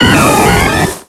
Cri d'Empiflor dans Pokémon X et Y.